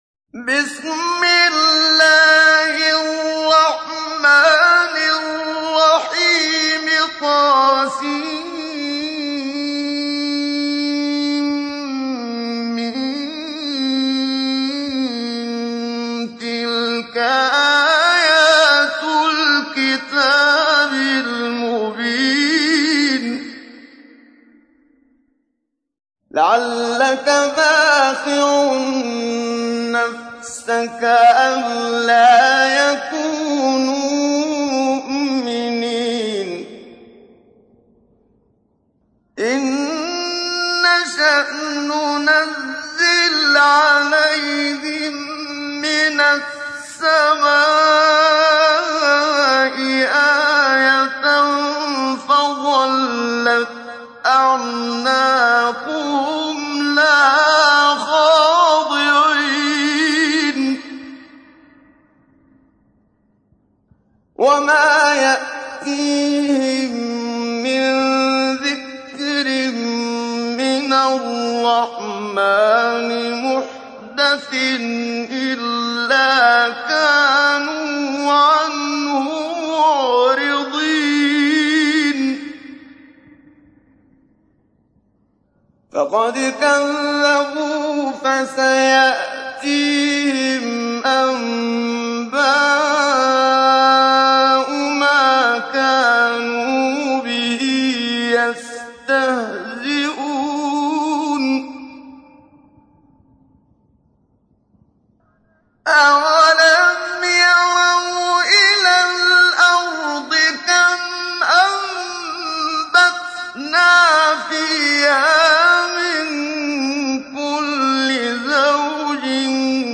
تحميل : 26. سورة الشعراء / القارئ محمد صديق المنشاوي / القرآن الكريم / موقع يا حسين